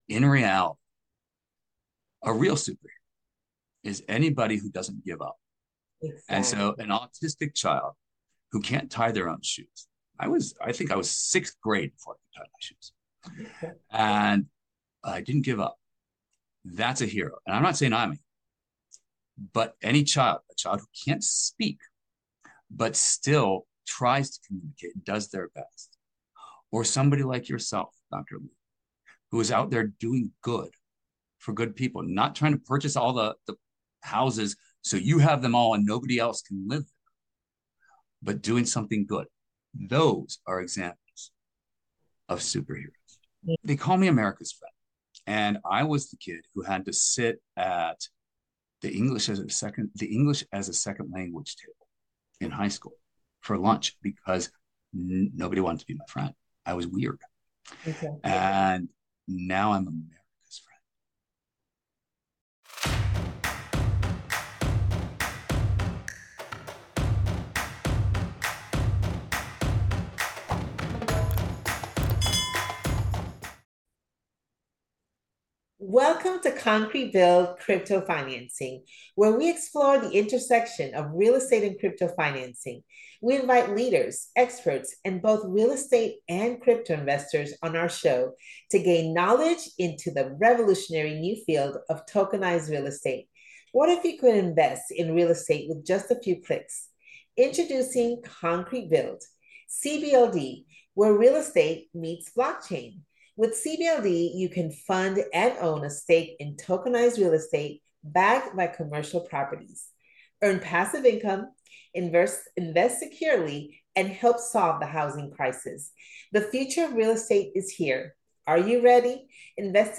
Concrete Build Crypto Financing, is a show that highlights the intersection of Real Estate and Crypto and navigating the two investments The goal is to highlight the revolutionary nature of Crypto Financing of Real Estate and Tokenization of Commercial Property. Your story matters and will resonate with many who need the knowledge The recording environment is casual and comfortable - I want guests to feel at ease and always start with a meditation before the interview begins. Authenticity is valued over polish, so guests are encouraged to speak honestly without filtering their thoughts.